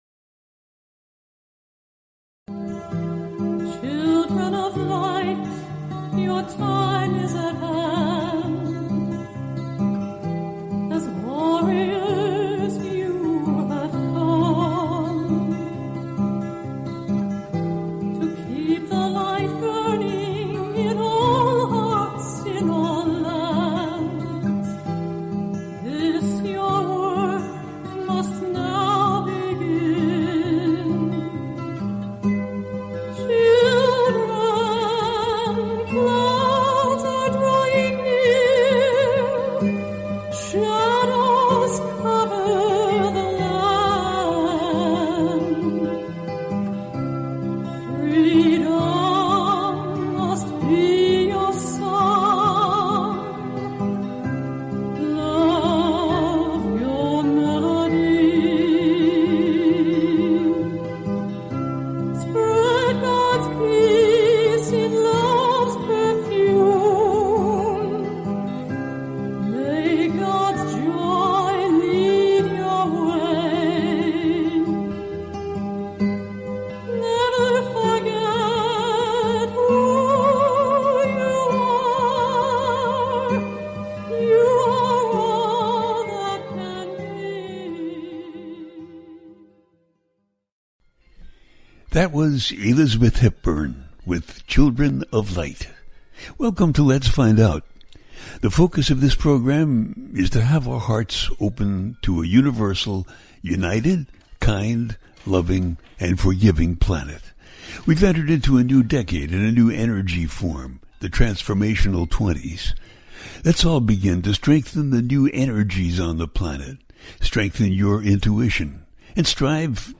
Guided Meditation
The listener can call in to ask a question on the air.
Each show ends with a guided meditation.